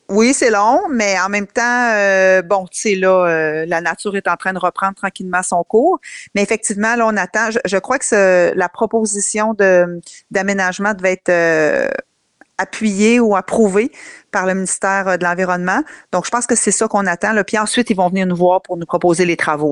En entrevue, la mairesse, Geneviève Dubois, a résumé la situation.